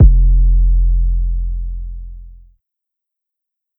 IGOR 808.wav